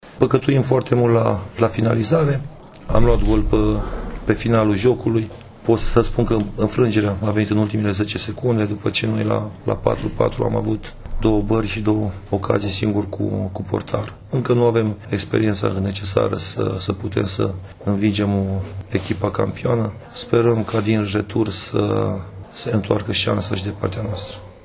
Ascultaţi declaraţiile antrenorilor în urma acestei partide aprig disputate